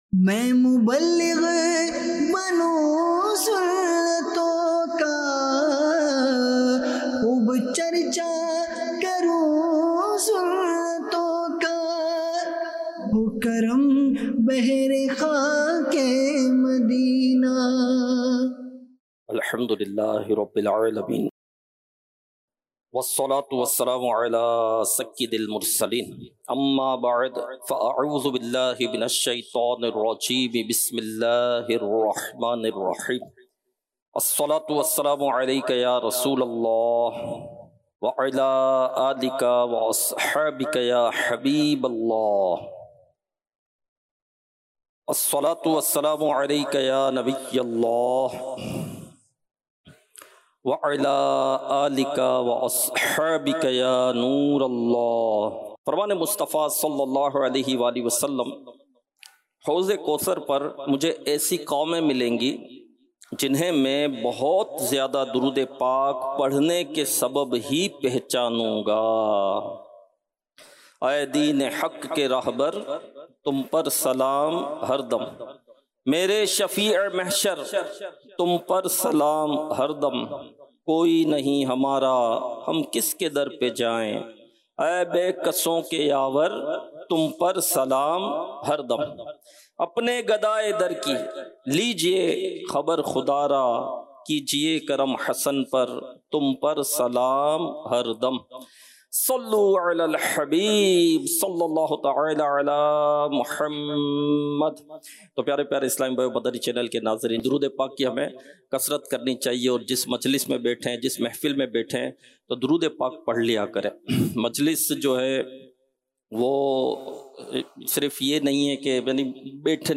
Sunnaton Bhara Bayan - Jumma Bayan